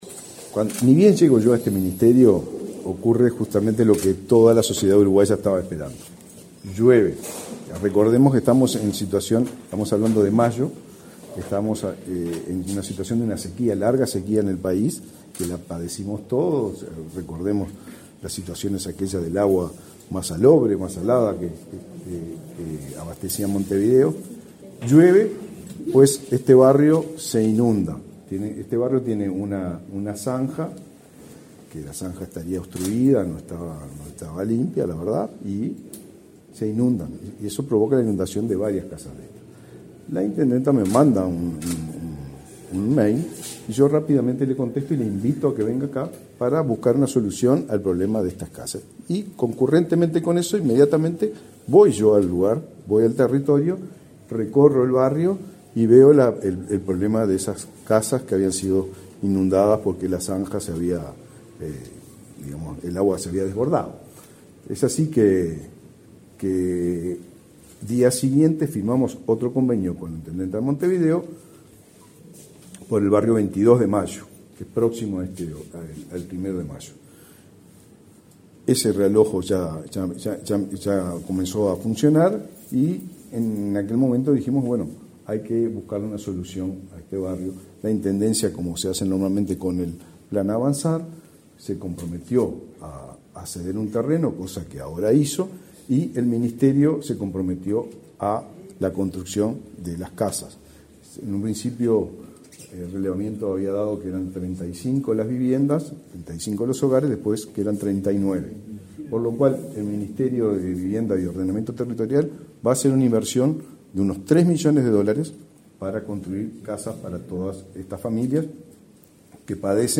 Declaraciones a la prensa del titular del MVOT, Raúl Lozano
Declaraciones a la prensa del titular del MVOT, Raúl Lozano 07/12/2023 Compartir Facebook X Copiar enlace WhatsApp LinkedIn Tras participar en la firma de un convenio entre el Ministerio de Vivienda y Ordenamiento Territorial (MVOT) y la Intendencia de Montevideo, este 7 de diciembre, para el realojo de familias del asentamiento 1.° de Mayo, el titular de la citada cartera realizó declaraciones a la prensa.